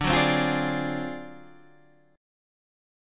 D 7th Sharp 9th Flat 5th